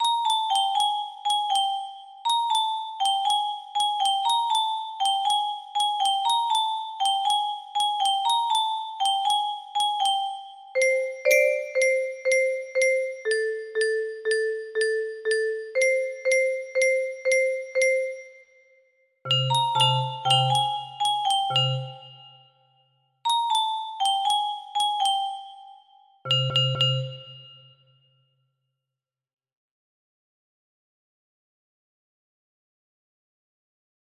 The best song for me yes yessssssssssssssssssssssssssssssssss music box melody